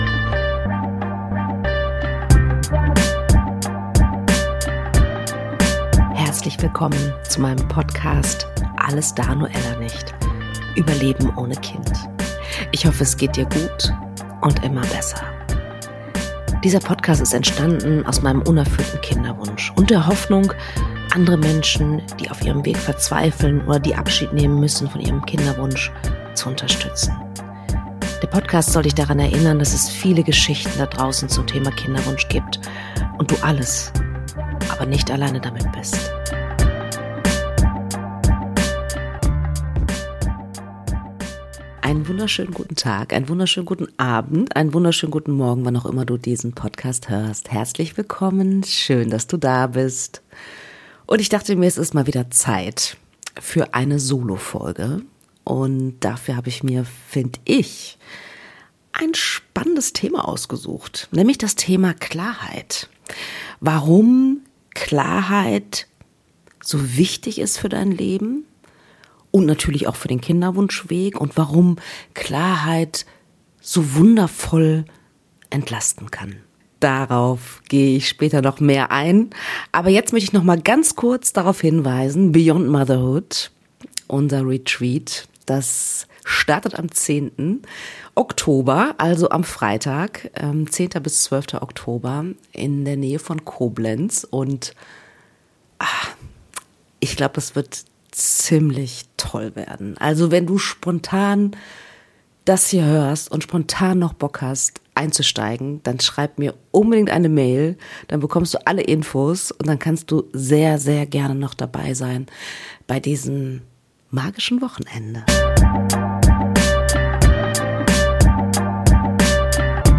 Eine neue Solofolge erwartet dich in meinem Podcast „Alles da, nur Ella nicht“.